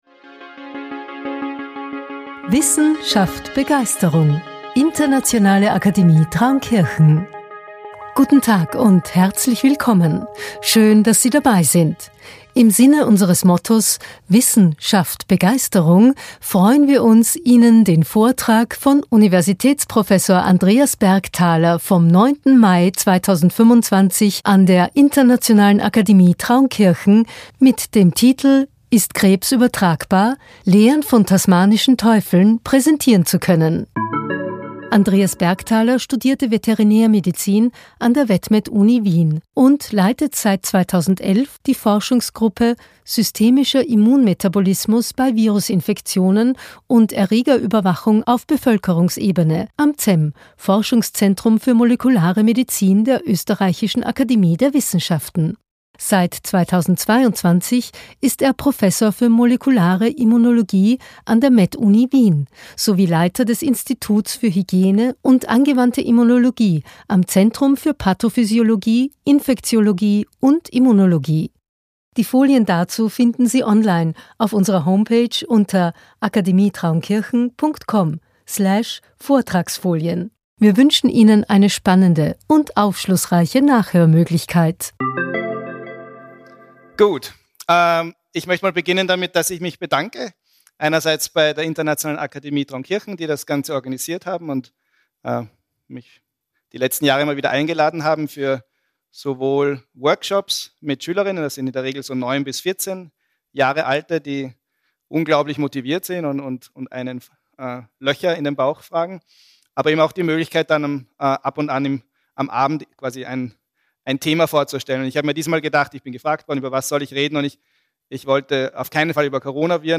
Lehren von Tasmanischen Teufeln ~ IAT ScienceCast - Einblicke in Naturwissenschaft und Technik aus dem Klostersaal Traunkirchen Podcast